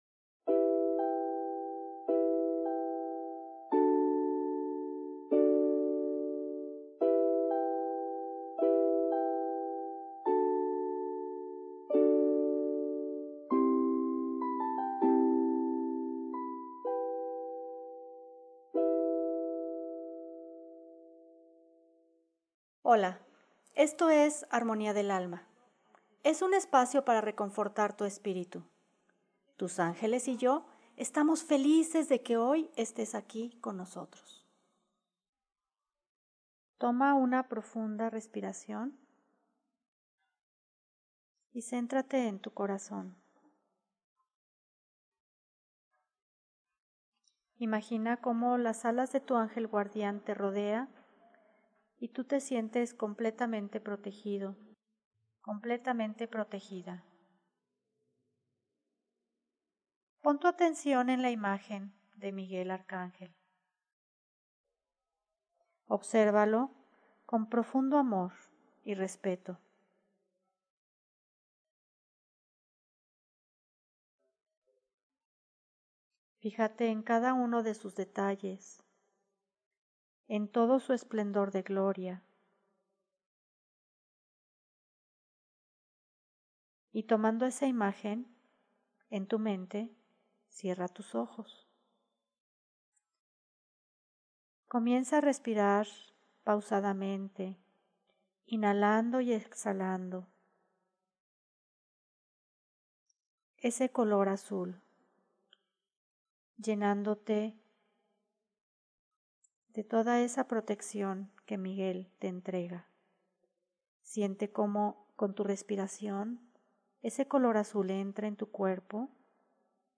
CLASE 7: